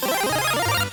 The Power-Up sound as heard in Super Smash Bros. for Nintendo 3DS and Wii U.
This media file is poor quality.
Specifics: Audio has background music
SSB4_Power-Up_sound.wav